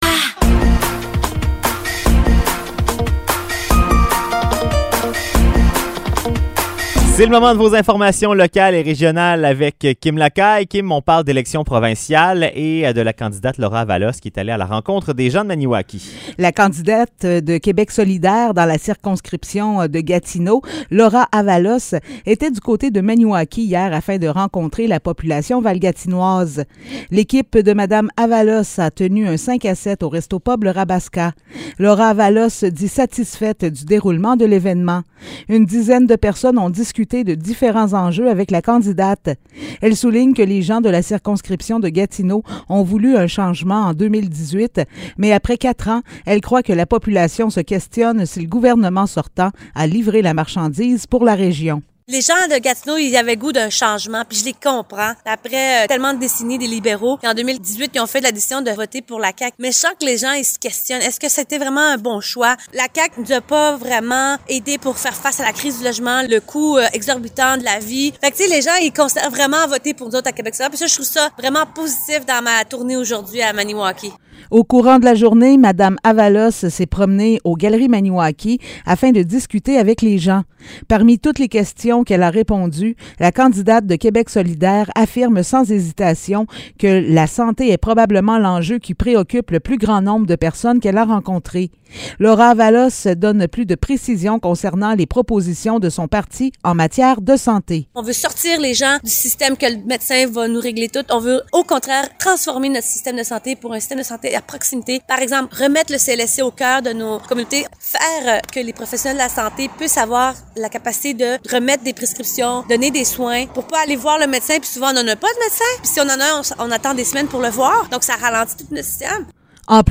Nouvelles locales - 16 septembre 2022 - 16 h